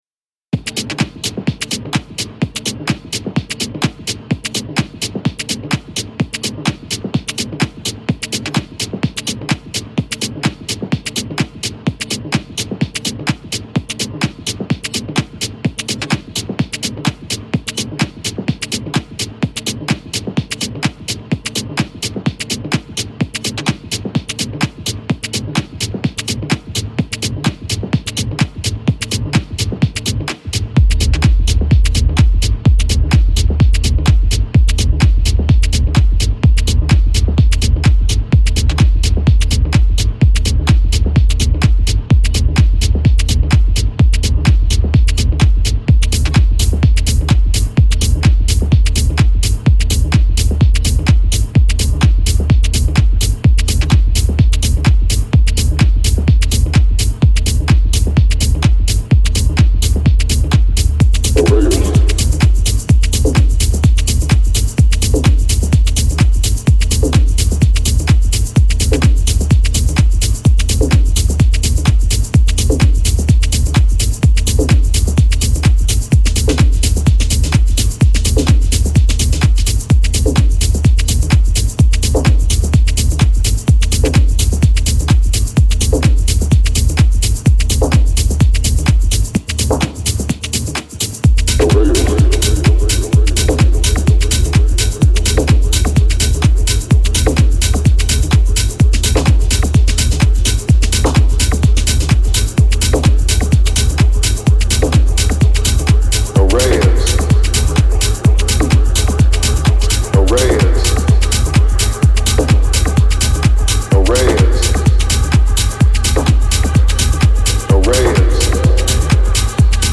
My 30 minute mix was interrupted by a skipping cdr.